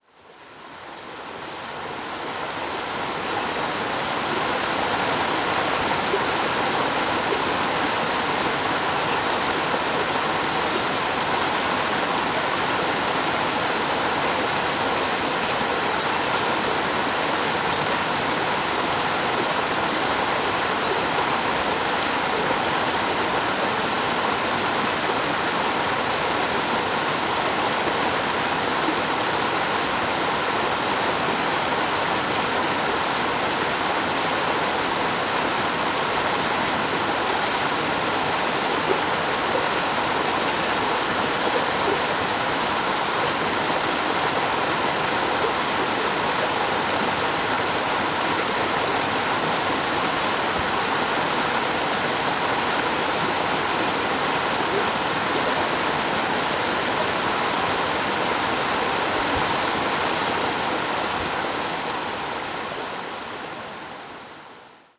Recorded these four soundscapes during a walk on the Copley Ridge and Knarston Creek Loop trail in the hills above Lantzville on December 6, 2021.
1. Knarston Creek below the waterfall